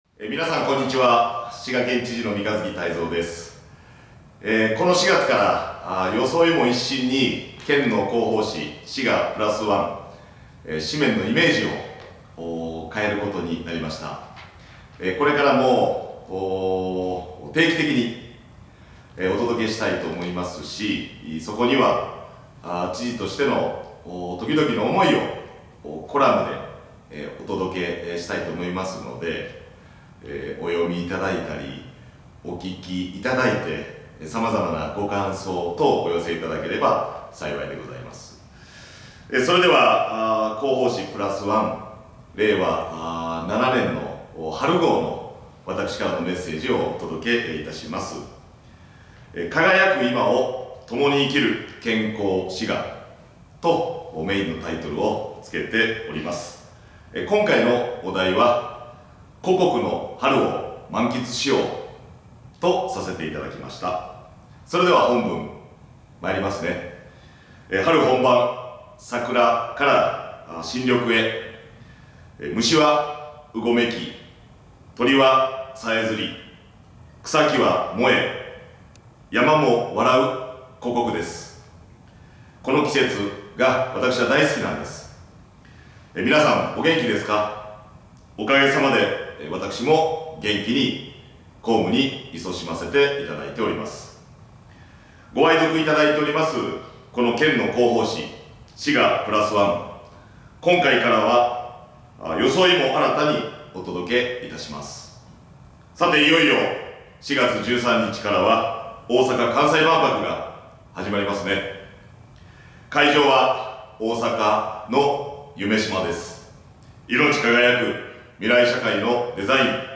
みんなでプラスワン！ vol.51 【音声版】みんなでプラスワン（知事朗読）Vol.51 (mp3:9 MB) ほっとサロン 「滋賀プラスワン」春号へのご感想や県政へのご意見などをお寄せいただいた方の中から抽選でプレゼントが当たります！